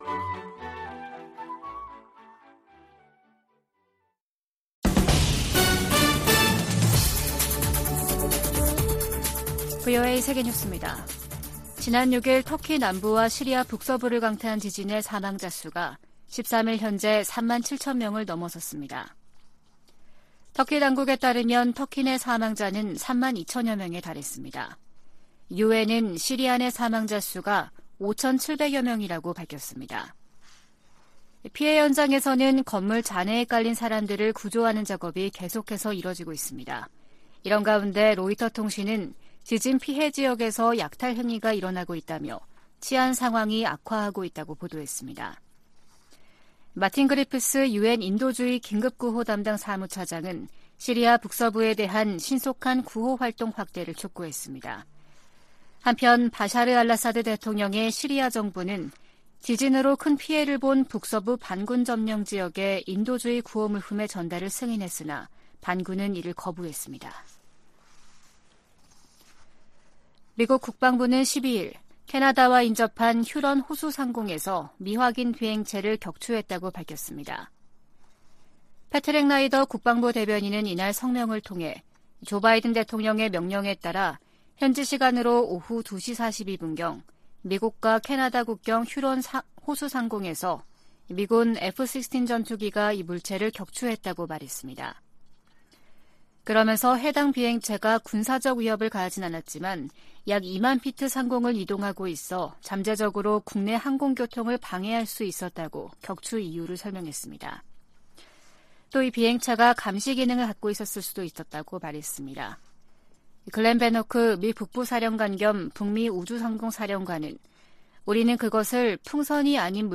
VOA 한국어 아침 뉴스 프로그램 '워싱턴 뉴스 광장' 2023년 2월 14일 방송입니다. 한국 정부가 사이버 분야에 첫 대북 독자 제재를 단행했습니다. 미 국무부는 북한이 고체연료 ICBM을 공개한 것과 관련해, 외교적 관여를 통한 한반도 비핵화 의지에 변함이 없다는 입장을 밝혔습니다. 북한과 러시아 간 군사협력이 한반도에도 좋지 않은 영향을 끼칠 것이라고 백악관이 지적했습니다.